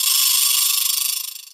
DDWV POP PERC 7.wav